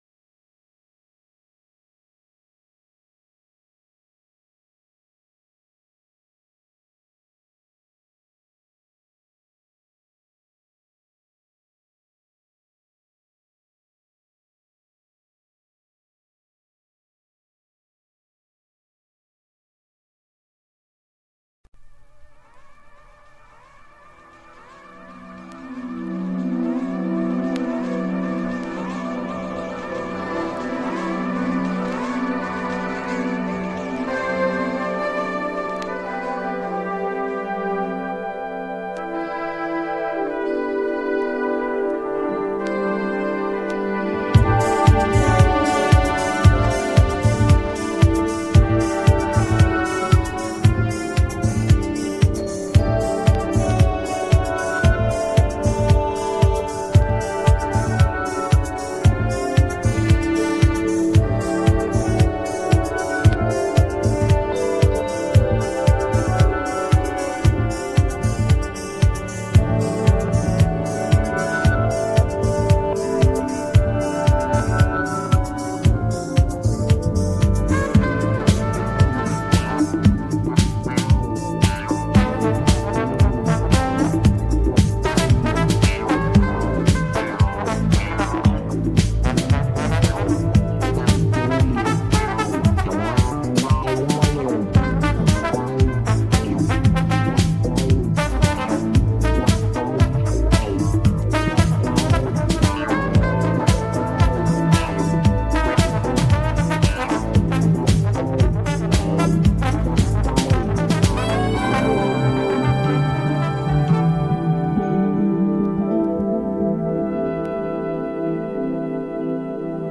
P-Funk